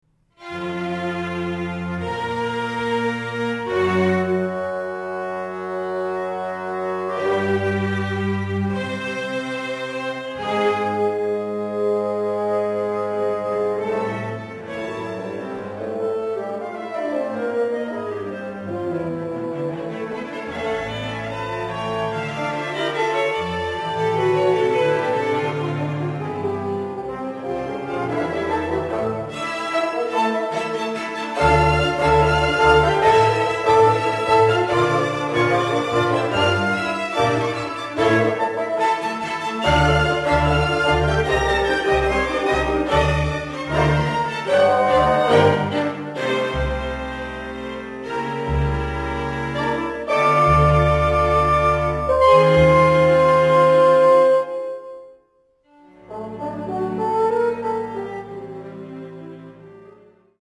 Poco Adagio – Allegro Moderato